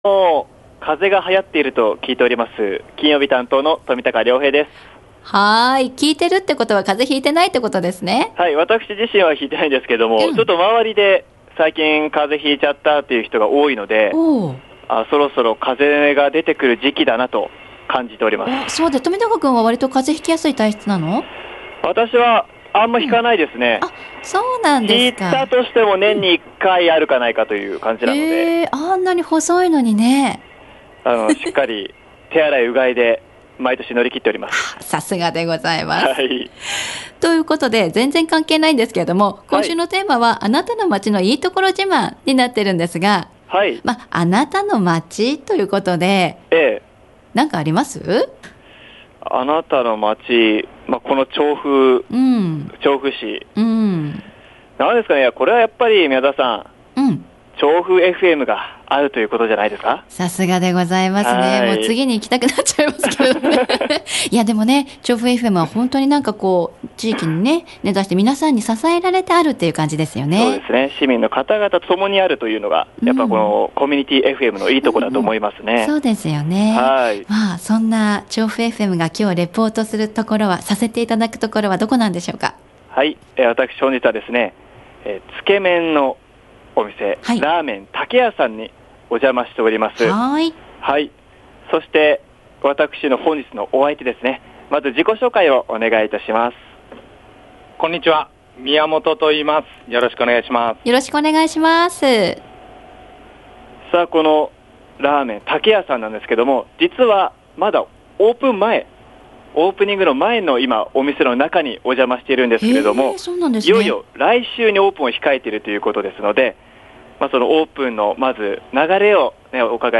街角レポート
さて本日は、つけ麺 らあめんのお店「竹屋」さんにお伺いしました☆ 今月２９日にオープンのお店で、オープン準備中の店内にお伺いしました！！